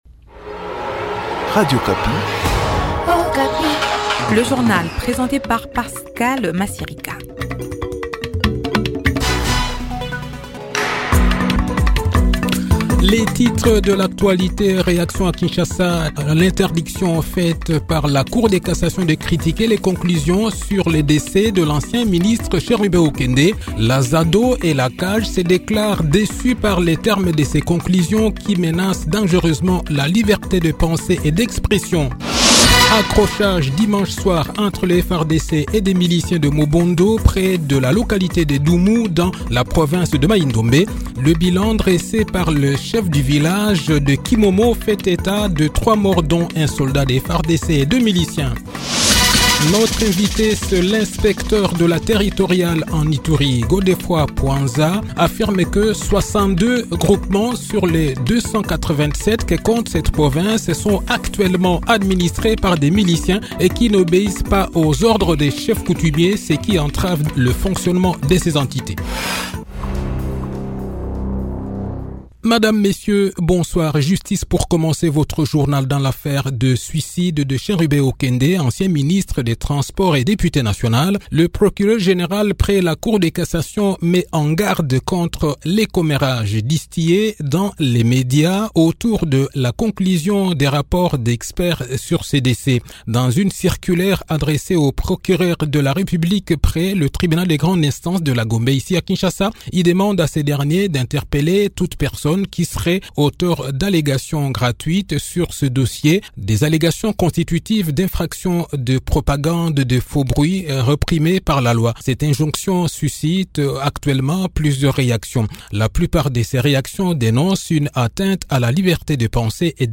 Le journal de 18 h, 5 mars 2024